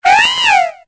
Cri de Léopardus dans Pokémon Épée et Bouclier.